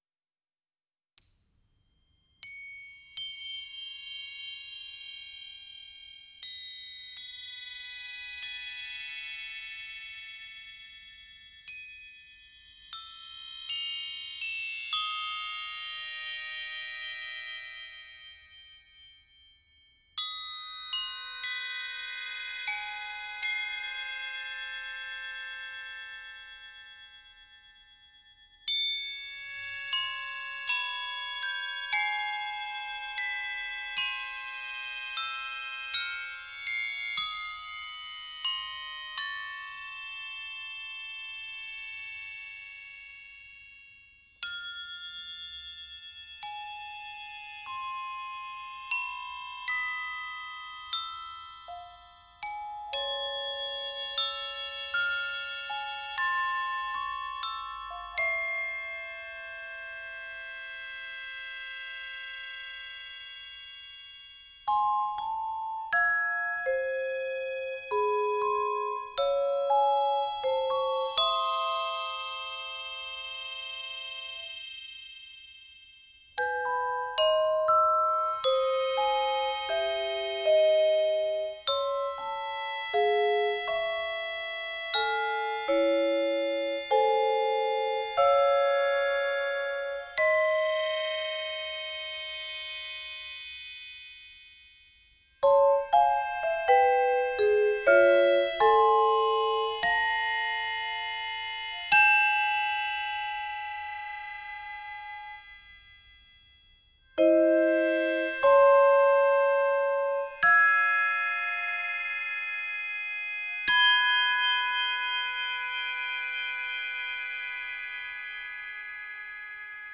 percusión percussion
para percusión y electroacústica for percussion and tape